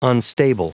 Prononciation du mot unstable en anglais (fichier audio)
Prononciation du mot : unstable